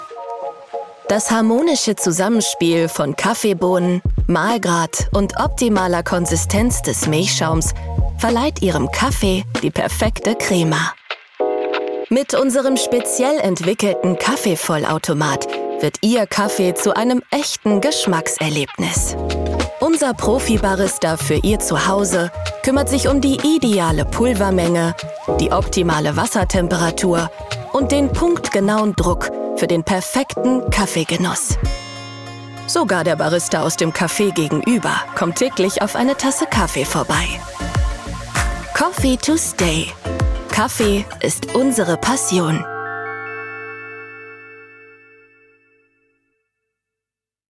Natürlich, Warm, Freundlich, Sanft
Kommerziell